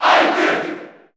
Category: Crowd cheers (SSBU) You cannot overwrite this file.
Ike_Cheer_Japanese_SSB4_SSBU.ogg